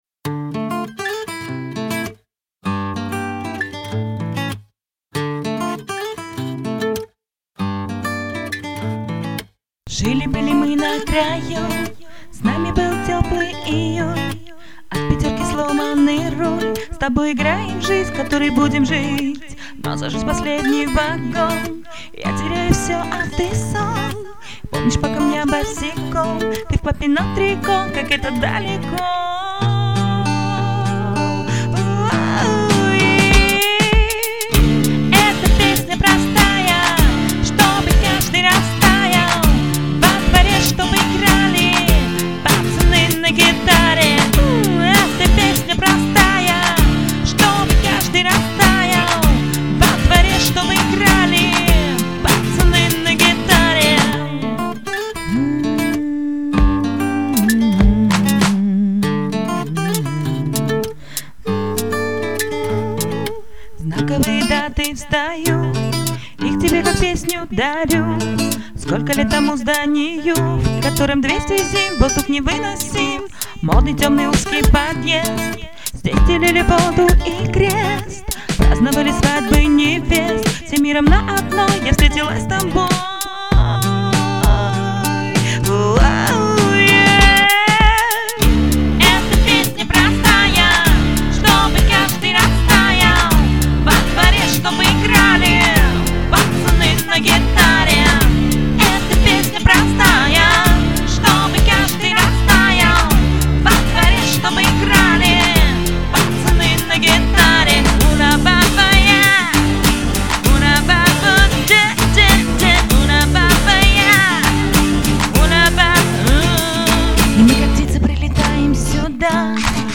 Очень ты ее динамично спела!